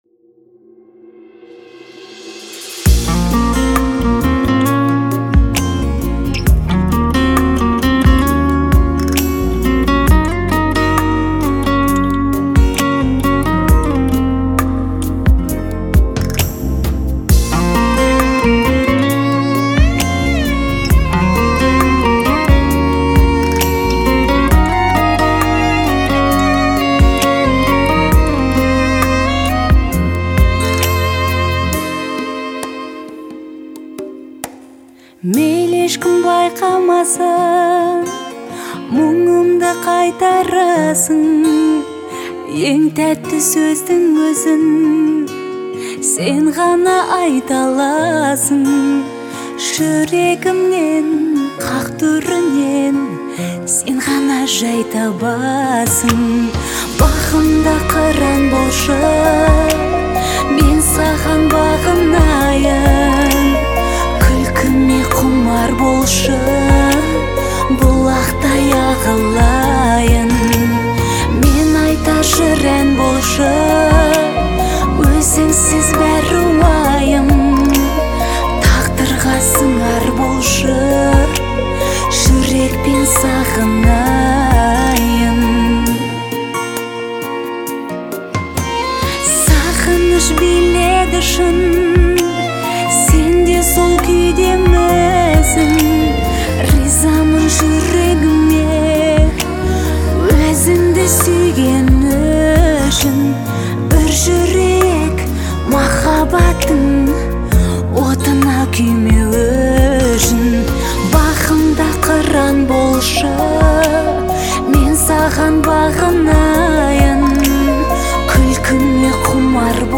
это трек в жанре казахской поп-музыки